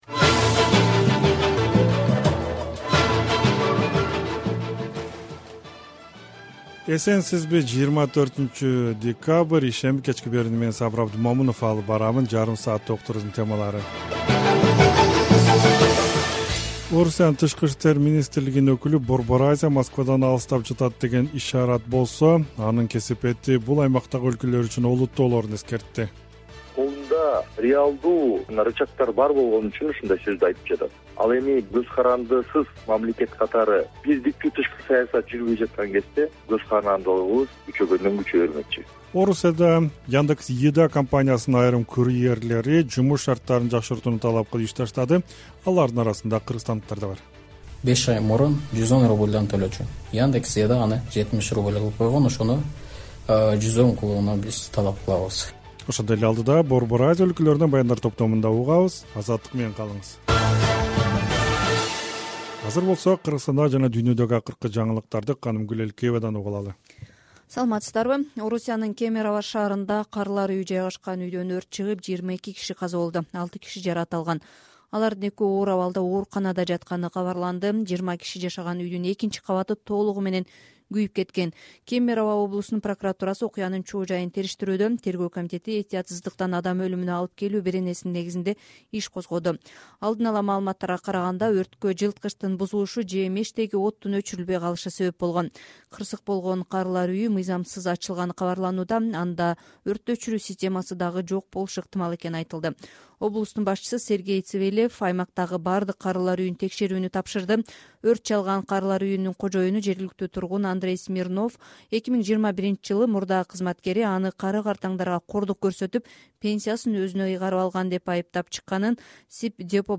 Кечки радио эфир | 24.12.2022 | Москванын Борбор Азияга эскертүүсү